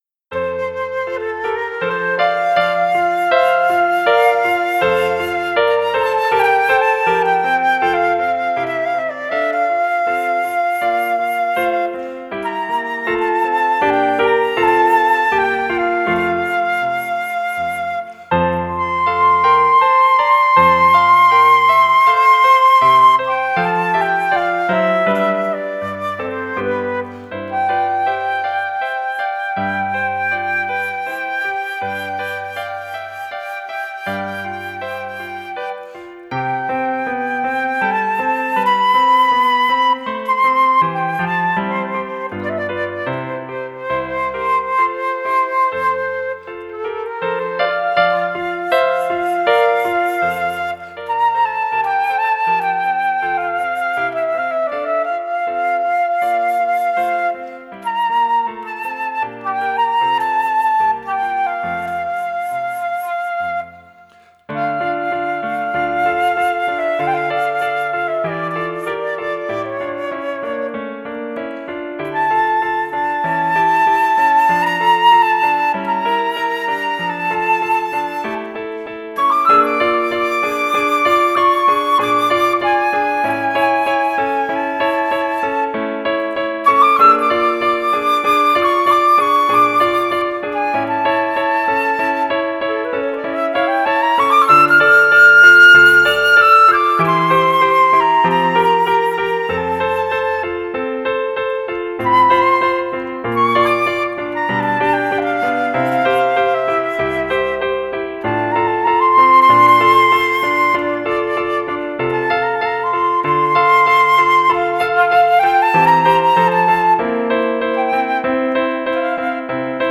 Die panfluit se klank voer dadelik die luisterraar meeAL die aandag is op die bruidspaar gevestig.